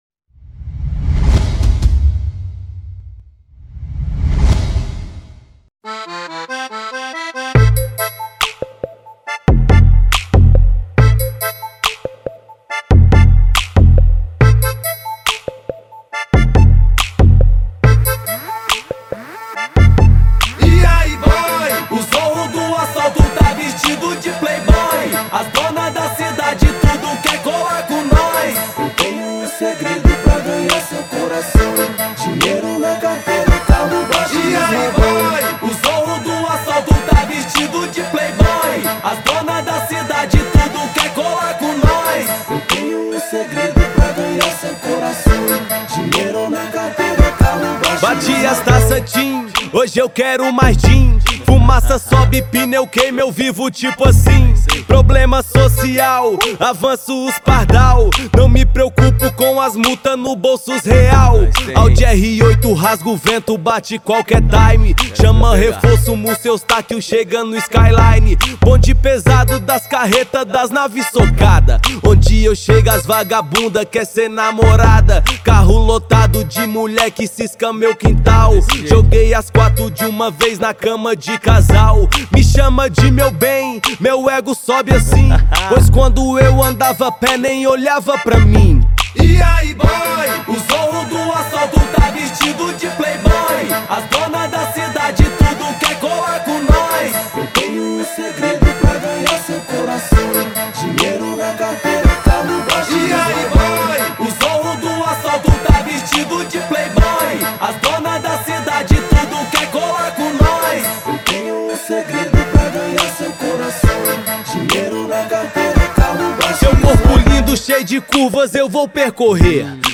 2025-03-17 19:25:12 Gênero: Rap Views